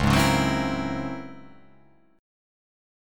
C#mM13 chord